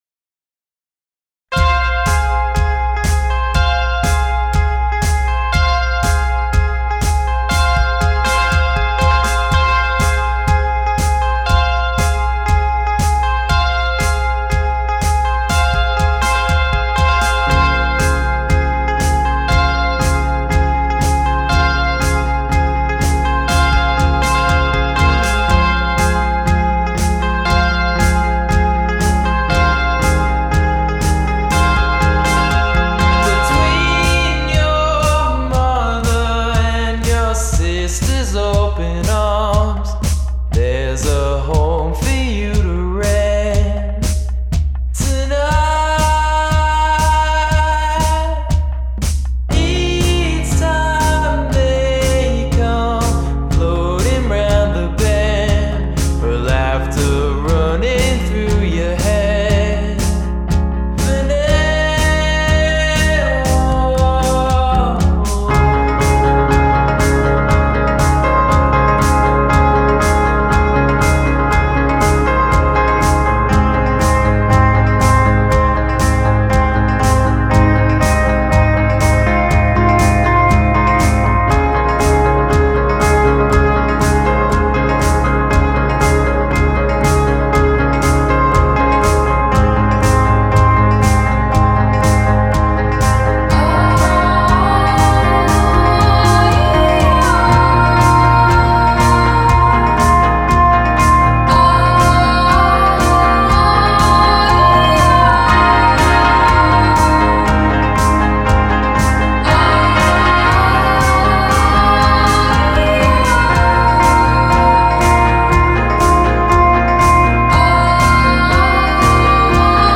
tangles of glistening guitar